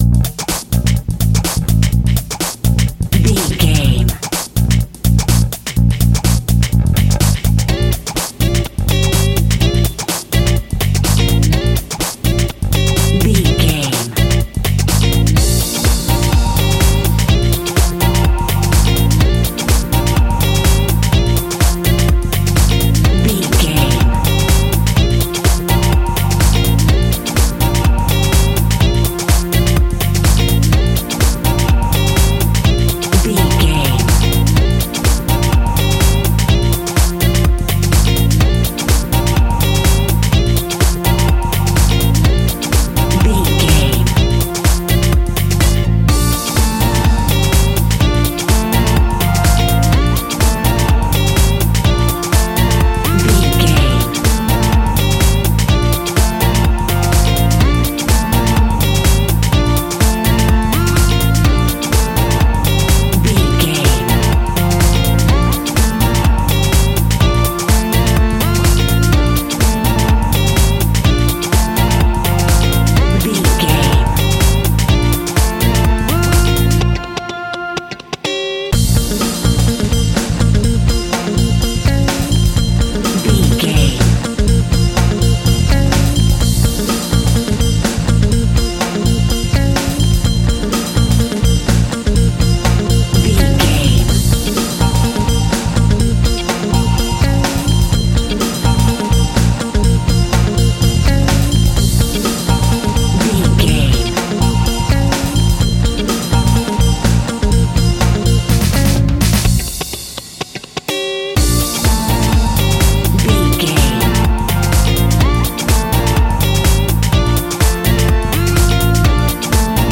Ionian/Major
B♭
groovy
uplifting
driving
energetic
electric piano
synthesiser
bass guitar
electric guitar
drums
drum machine
disco house
electronic funk
bright
upbeat
synth bass
synth lead
Synth Pads
clavinet
horns